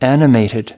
2 animated (adj) /ˈænɪmeɪtɪd/ Hoạt hình